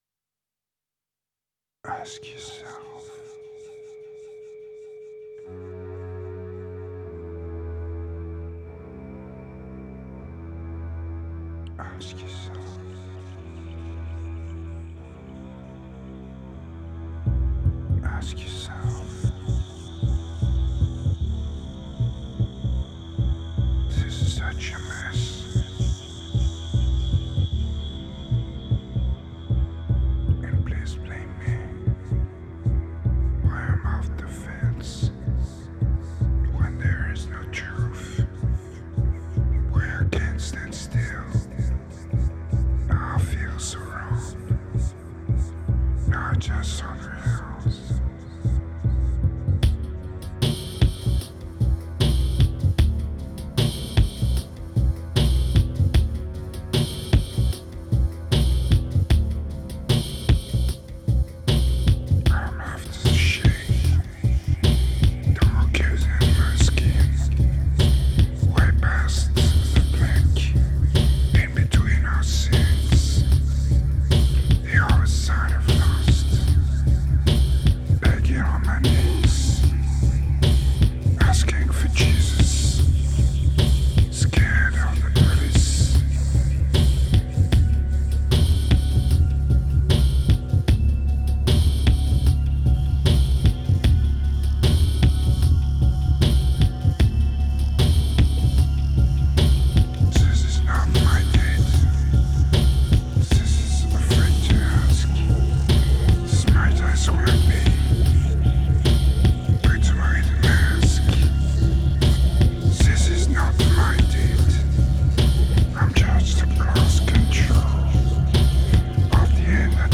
Pure remote desire, strong medication and raw electronica.
2426📈 - -33%🤔 - 76BPM🔊 - 2011-01-13📅 - -821🌟